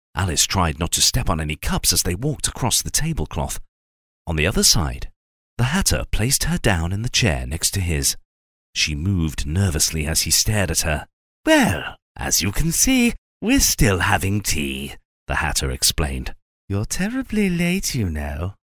Male
Adult (30-50), Older Sound (50+)
Very versatile, warm, rich, authentic British accent, charismatic and friendly with a comedic twist of fun when needed!
Narration
Storytelling
Words that describe my voice are British, Warm, Conversational.